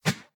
whoosh3.ogg